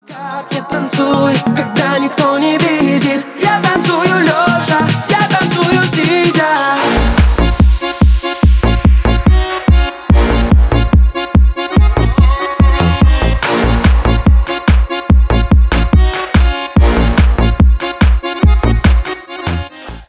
Поп Музыка
клубные # весёлые